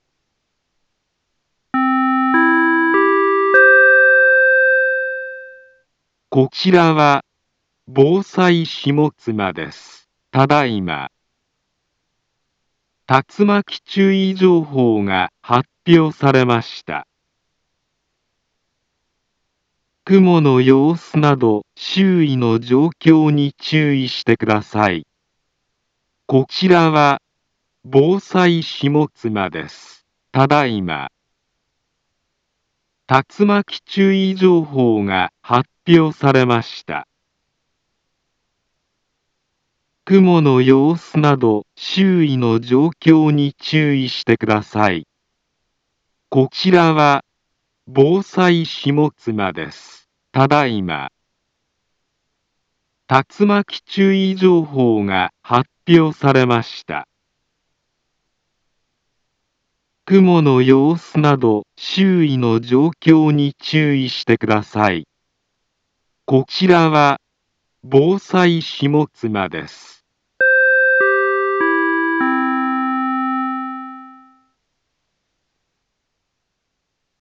Back Home Ｊアラート情報 音声放送 再生 災害情報 カテゴリ：J-ALERT 登録日時：2021-08-30 22:29:41 インフォメーション：茨城県北部、南部は、竜巻などの激しい突風が発生しやすい気象状況になっています。